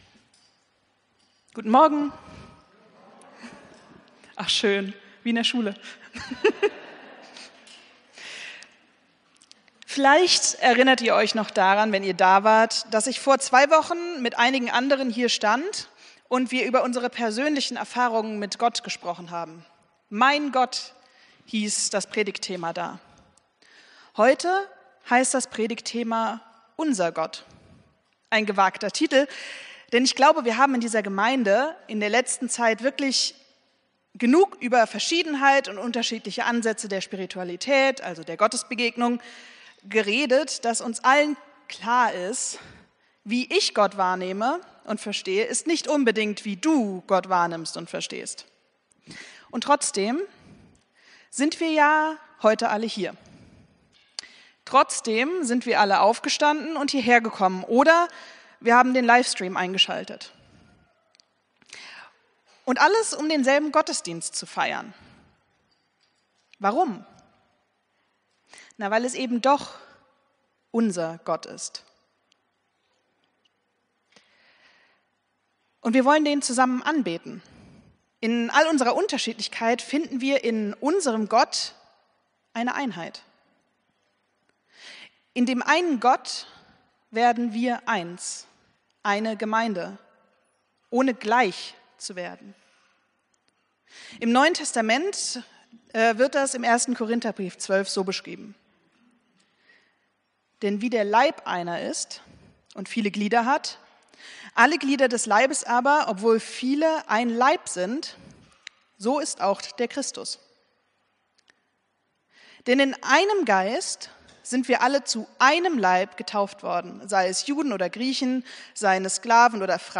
Predigt vom 06.07.2025